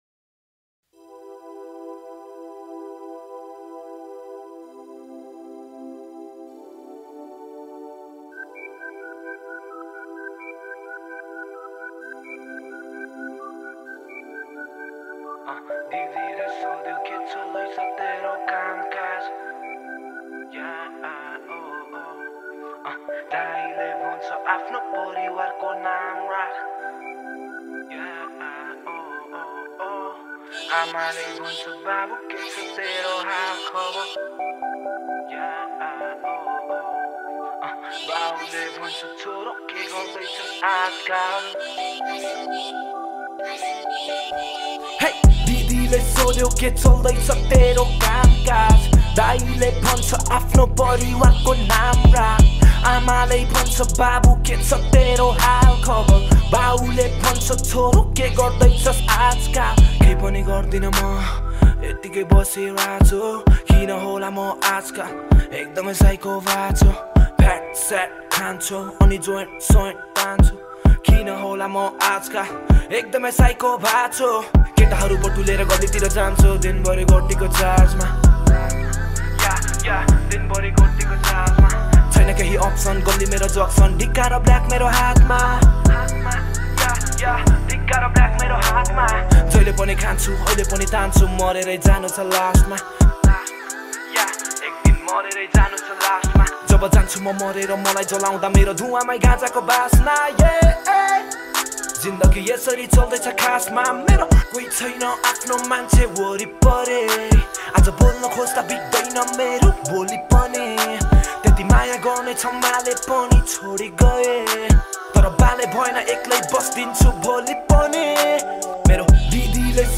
# Nepali Rap Gana Mp3